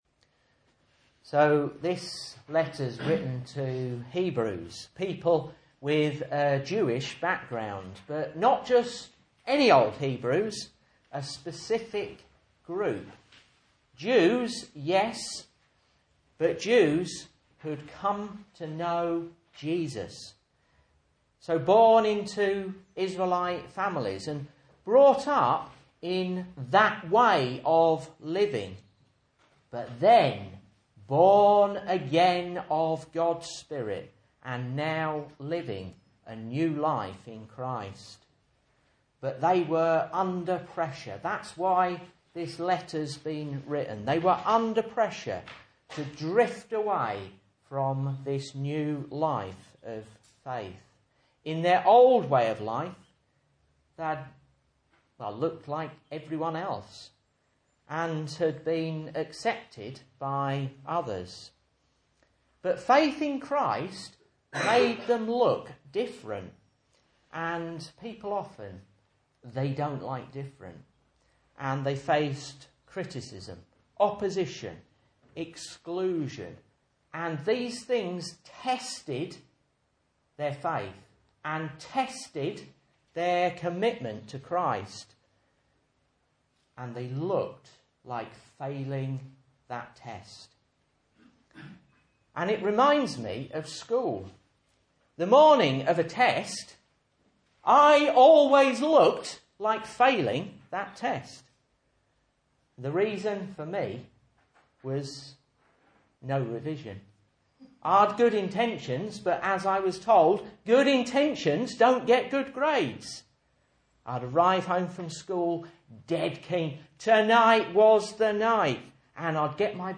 Message Scripture: Hebrews 1:4-13 | Listen